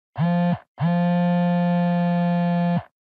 dokkaebi hacking sound sound effects
dokkaebi-hacking-sound